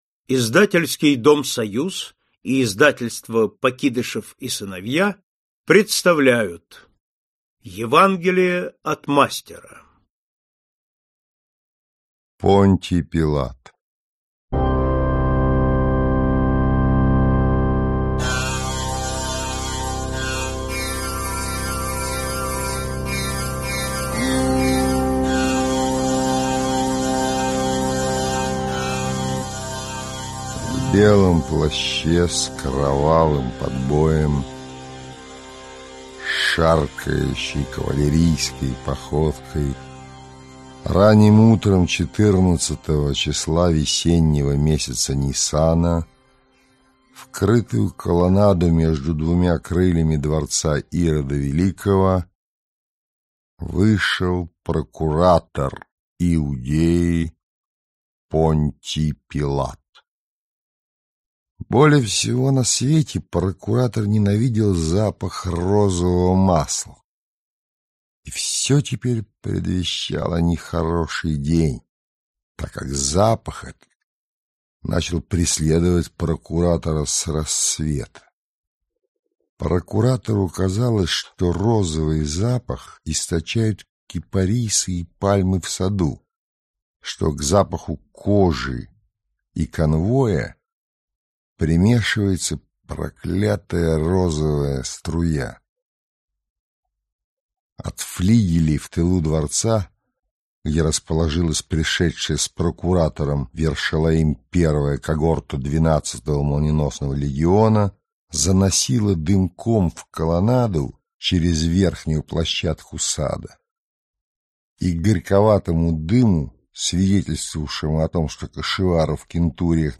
Аудиокнига Мастер и Маргарита. Библейская тема | Библиотека аудиокниг
Библейская тема Автор Михаил Булгаков Читает аудиокнигу Михаил Козаков.